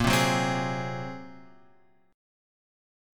A# 7th Suspended 2nd Sharp 5th